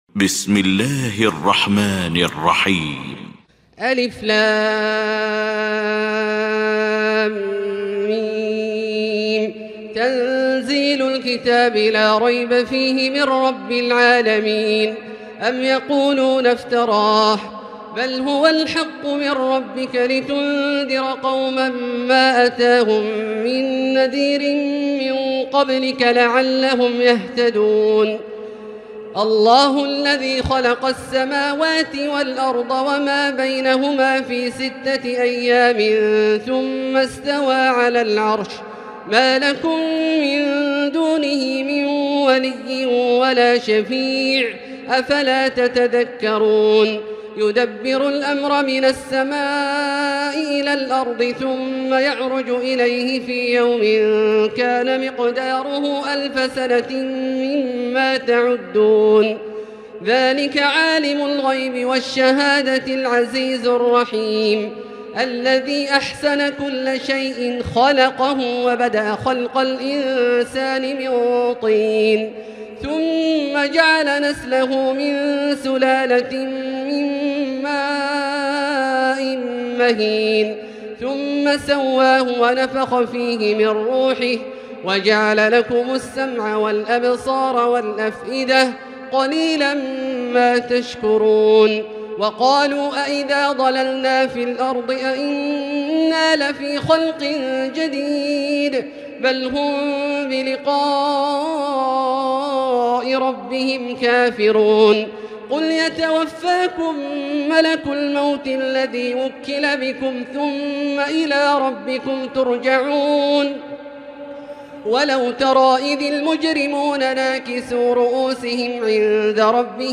المكان: المسجد الحرام الشيخ: فضيلة الشيخ عبدالله الجهني فضيلة الشيخ عبدالله الجهني السجدة